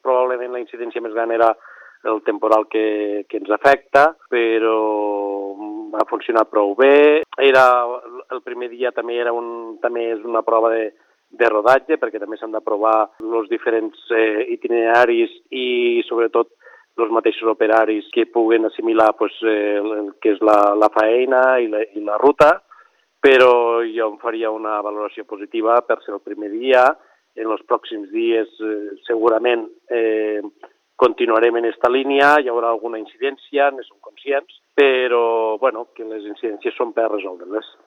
El president de l’EMD de Camp-redó, Damià Grau, n’ha fet una valoració positiva tot i que reconeix que s’està ajustant el servei i s’anirà corregint qualsevol contratemps que es pugui produir…